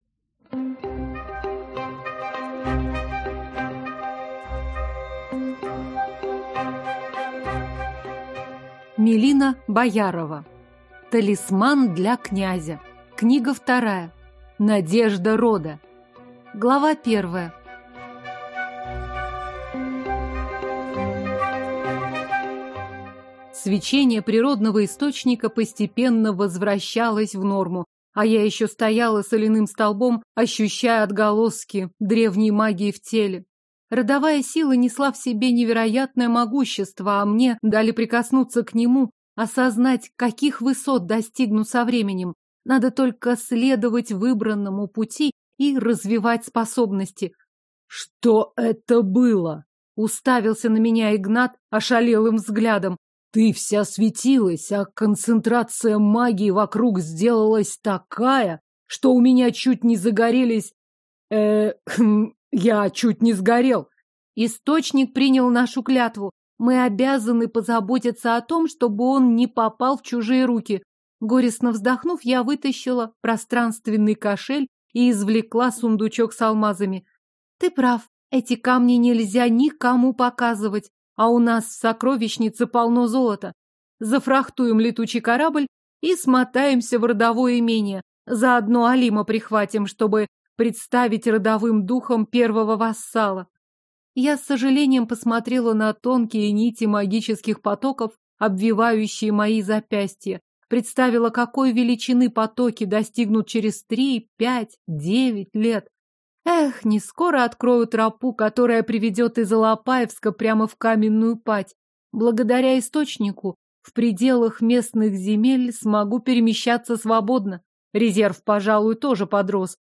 Aудиокнига Надежда рода